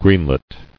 [green·let]